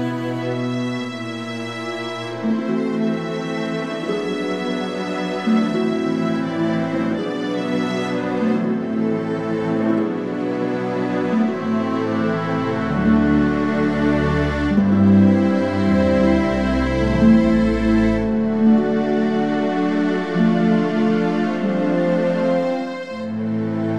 no Backing Vocals Musicals 2:42 Buy £1.50